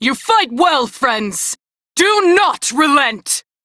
DRJCrewEnemyDefeat3890RJNLFem_en.ogg